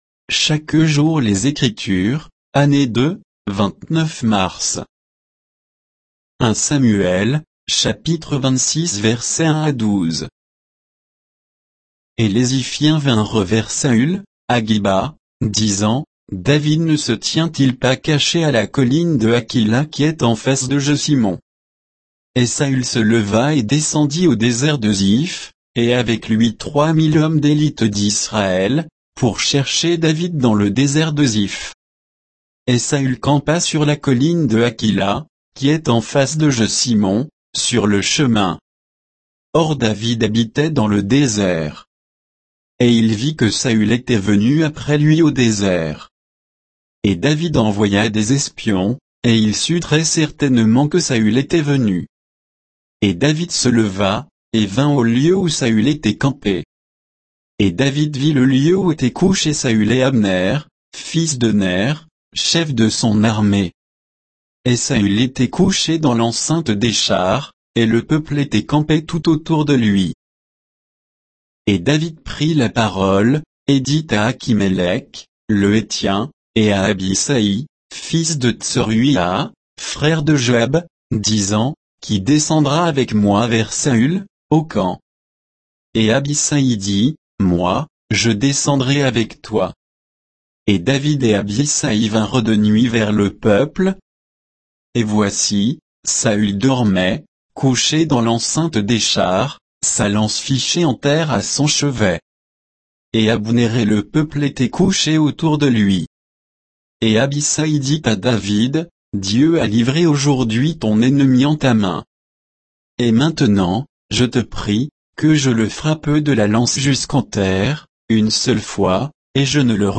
Méditation quoditienne de Chaque jour les Écritures sur 1 Samuel 26